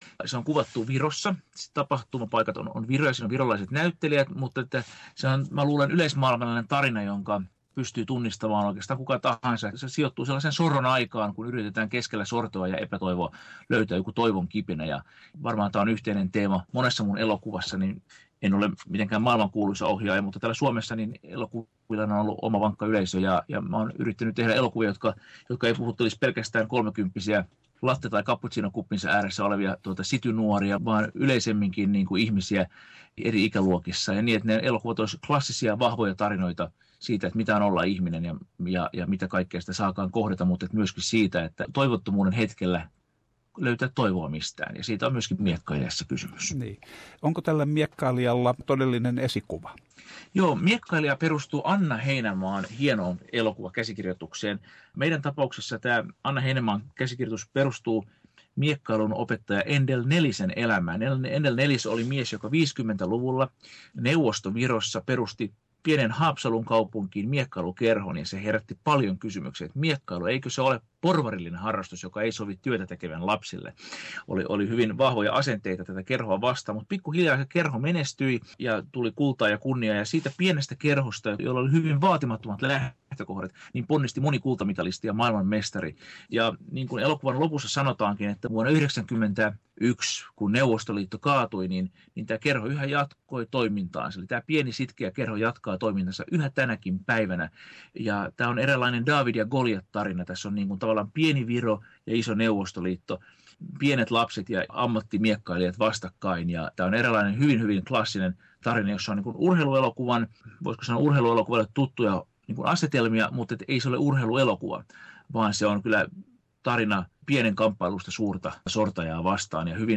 Klaus Härö haastattelu